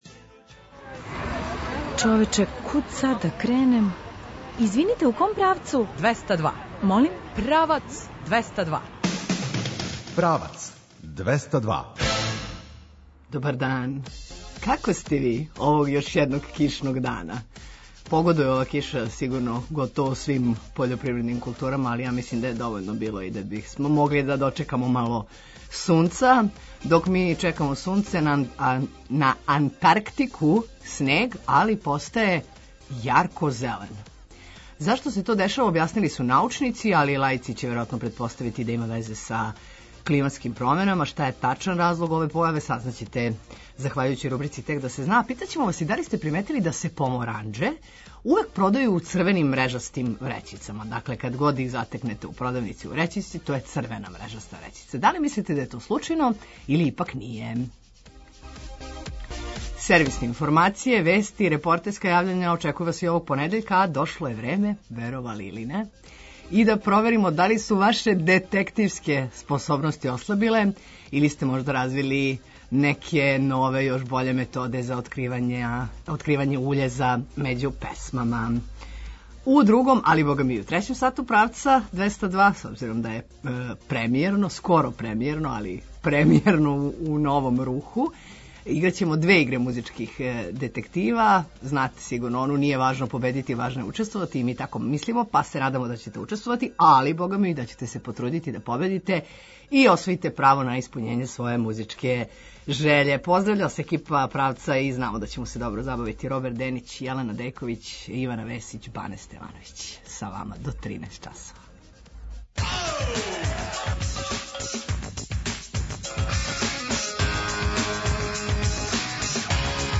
Сервисне информације, вести и репортерска јављања очекују вас и овог понедељка а дошло је време и да проверимо да ли су ваше детективске способности ослабиле или сте можда развили неке нове, још боље методе за откривање „уљеза” међу песмама?